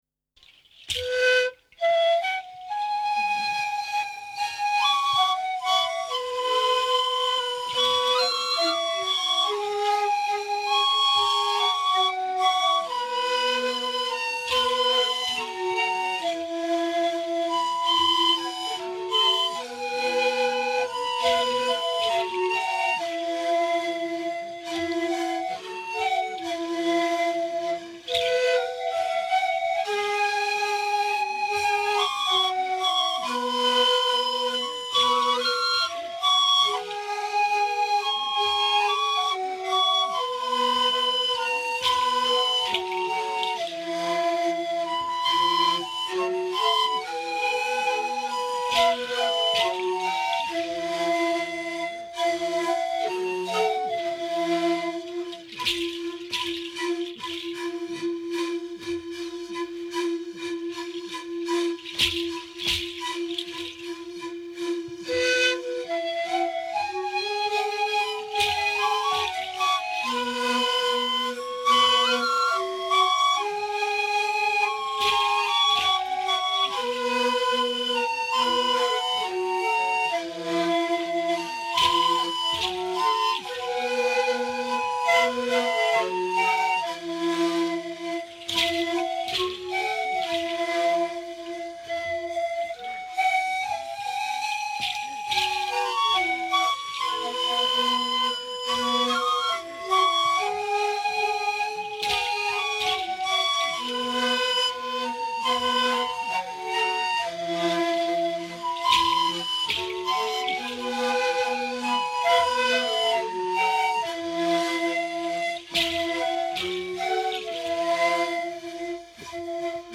Ensemble de flûtes de
De nombreux points différencient la culture musicale des ’Are ’are de Marau de celle des ’Are ’are de Malatai. L’origine mythologique de l’instrument par exemple, mais aussi la facture puisque les flûtes de Pan de Marau comportent deux rangs de tuyaux, le deuxième, ouvert aux deux extrémités agissant comme un résonateur modifiant le timbre… À noter aussi que les instruments de Marau semblent moins rigoureusement accordées que ceux de Malatai et que l’échelle n’est pas si facile à préciser que pour les flûtes de Malatai…
Le mode de jeu, aussi, est très différent puisque les musiciens se placent sur deux rangs et évoluent en dansant. Par ailleurs le nombre de musiciens n’est pas déterminé et on peut entendre parfois une alternance jeu des flûtes / chant.